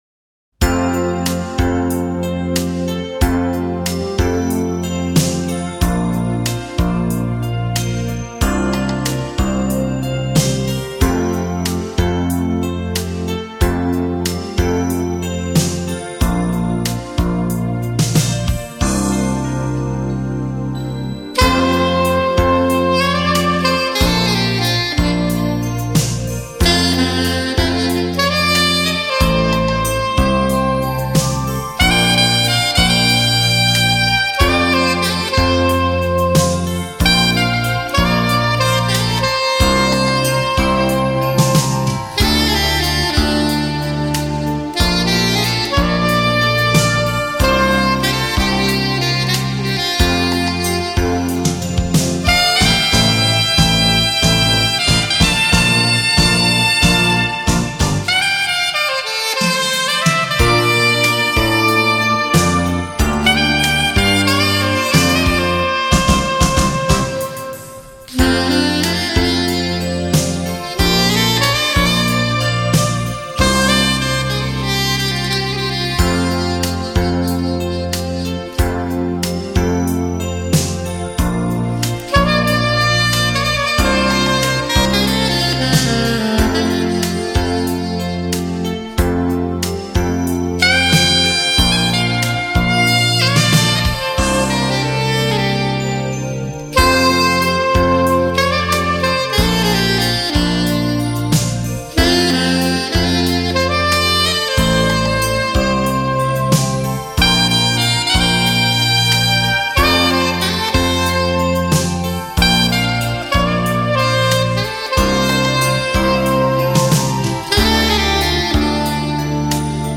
音乐类型: 民乐
唯美旋律　梦幻演绎　五星评级　收藏珍品
绕场立体音效　发烧音乐重炫